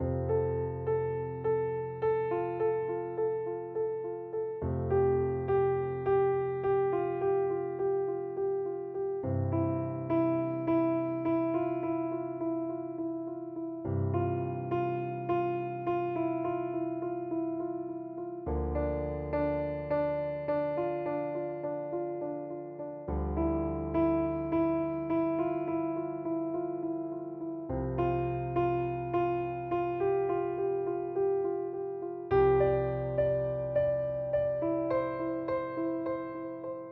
描述：鸣笛声、路人说话声、刮板声、油不断往外嗞的声音各种声音交杂一起，渐强又渐弱，起伏不停，充满烟火气，仿佛让人声临其境。
声道立体声